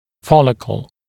[‘fɔlɪkl][‘фоликл]фолликул, пузырек, мешочек